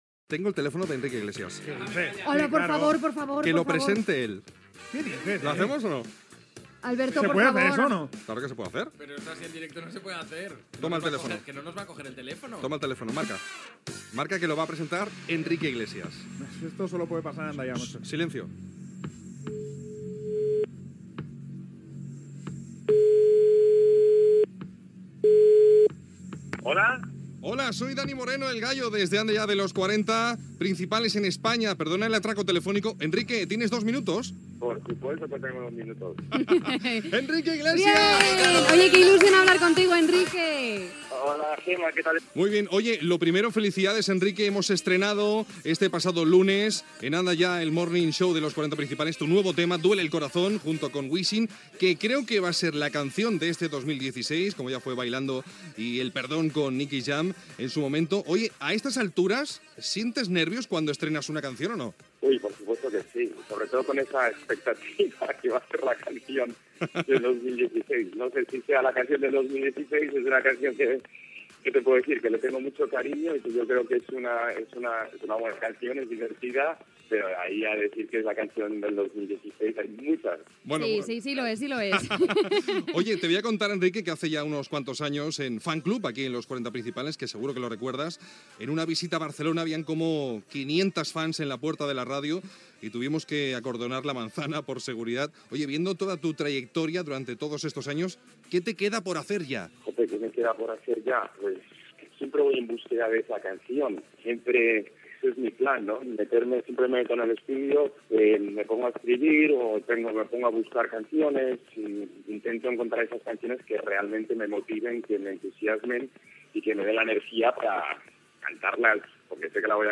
Trucada al cantant Enqrique Iglesias que presenta el disc "Duele el corazón"
Entreteniment